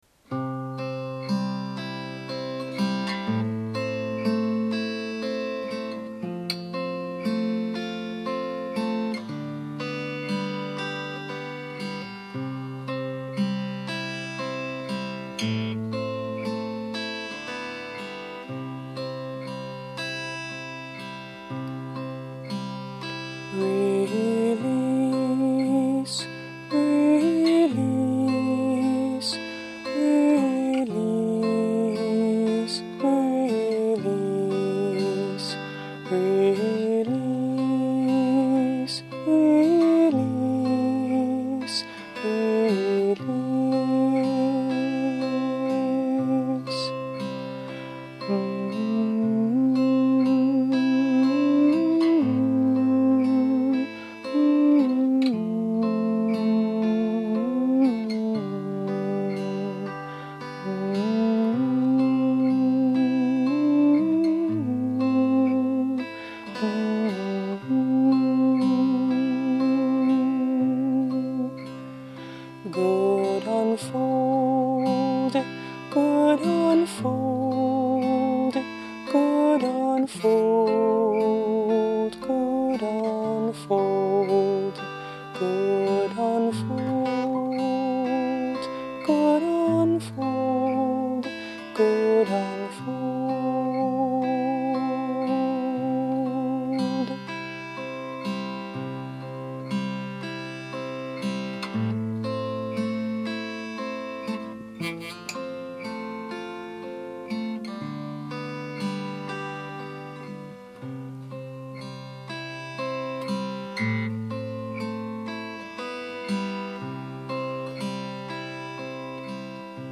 A meditational prayer chant/treatment.
Instrument: Tempo – Seagull Excursion Folk Acoustic Guitar
Verses have 7 repetitions.